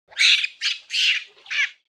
دانلود صدای میمون 7 از ساعد نیوز با لینک مستقیم و کیفیت بالا
جلوه های صوتی